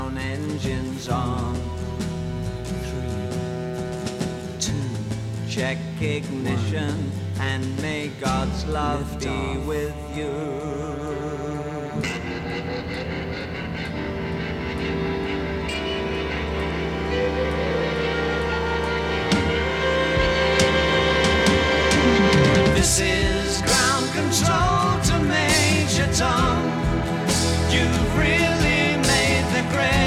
"templateExpression" => "Pop"